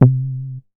MoogYubby 010.WAV